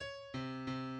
key Bm